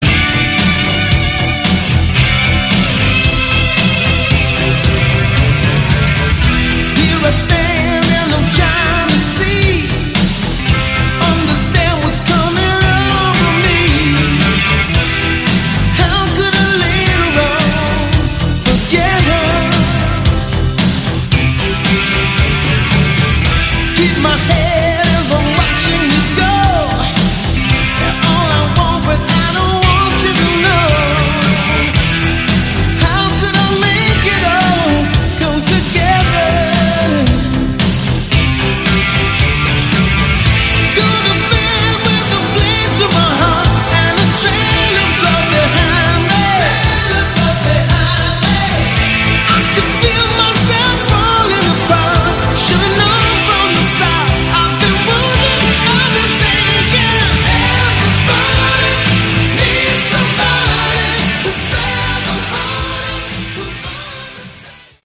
Category: Hard Rock
vocals, guitar, keyboards, bass
drums